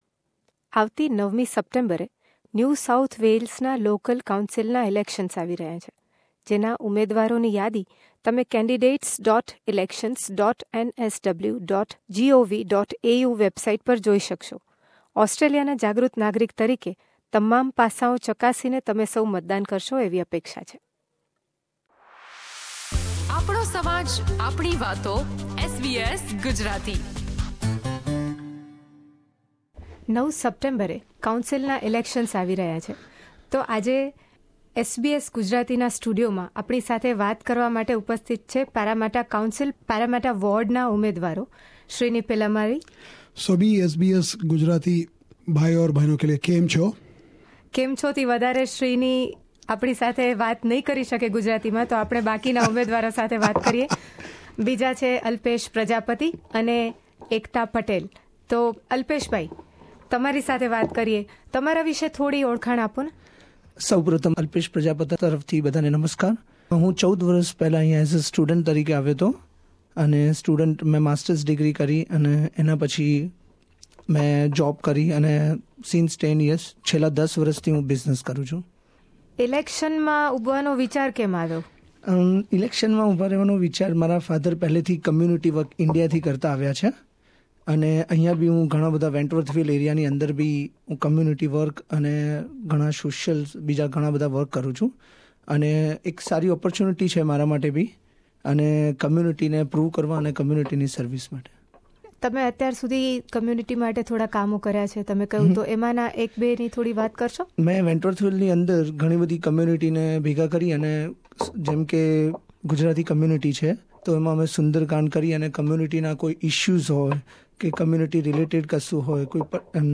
at SBS studio in Sydney